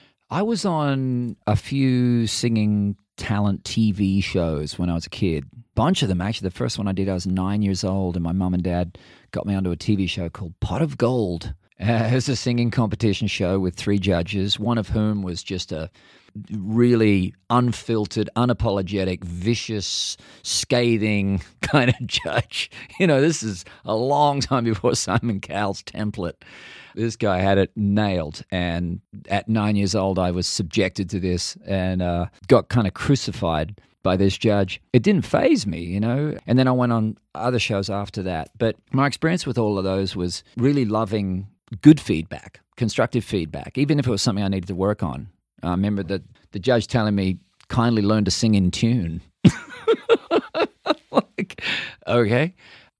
Keith Urban talks about going on talent competition shows when he was a kid.